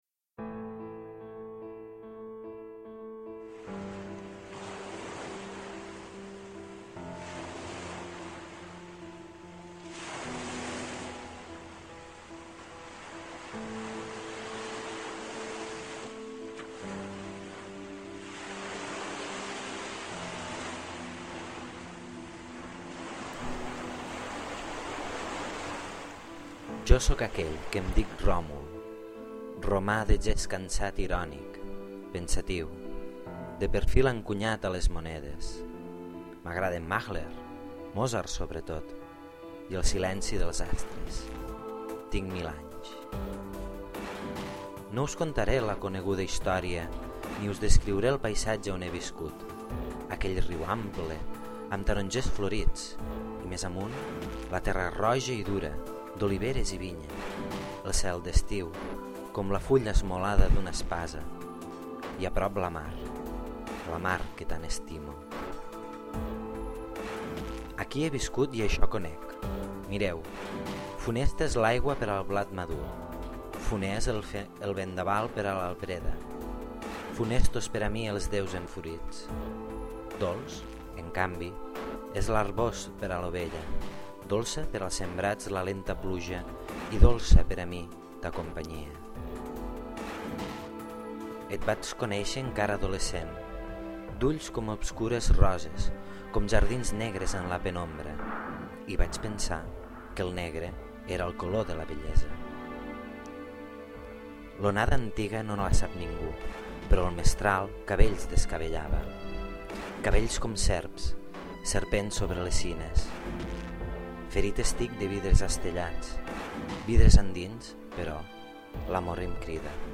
És la primera part del llibre L’ombra rogenca de la lloba, del Gerard Vergés, un llibre que m’estimo molt. El resultat no m’acaba de fer el pes ja que la cançó té un ritme que es marca massa (he intentat esborrar-lo i no hi ha hagut manera). Ara, hi he afegit uns efectes de mar a l’inici i al final, i me sembla que queden prou bé.